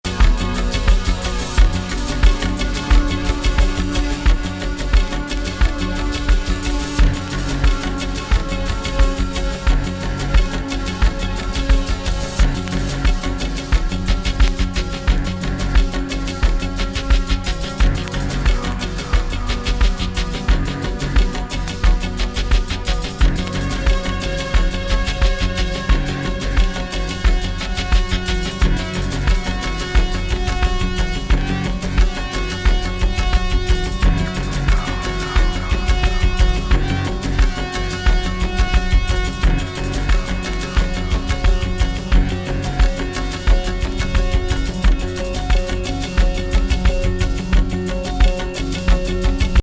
としたミニマル・サウンドにどこかエスノな雰囲気を感じるミニマル・ビート。
そして電子音使い!これぞオブスキュア。